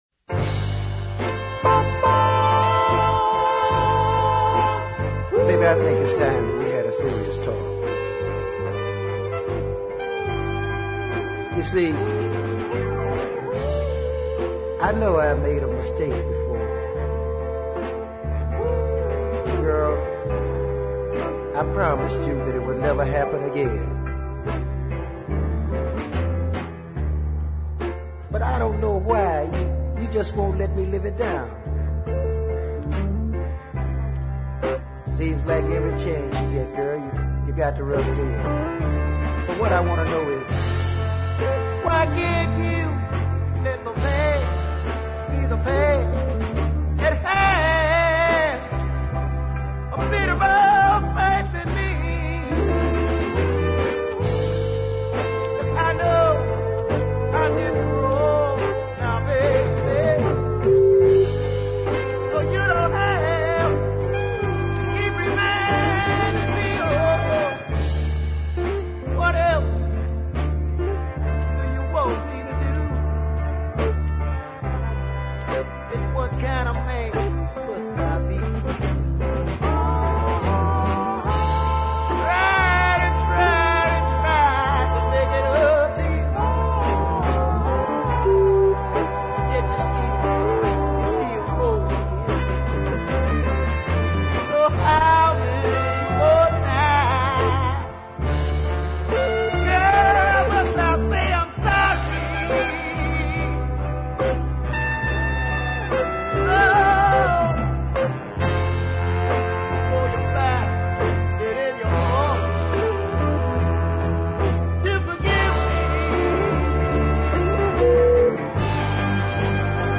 hoarse wailing and tasteful background harmonies